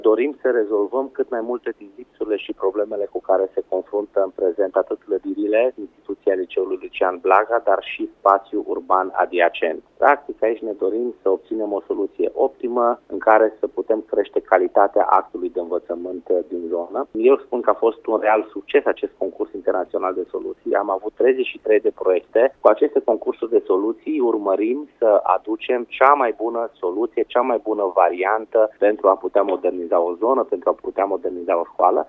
Viceprimarul Dan Tarcea, invitat marți, 7 martie, la Radio Cluj, a precizat că au fost depuse 33 de proiecte la concursul de soluții pentru modernizarea liceului: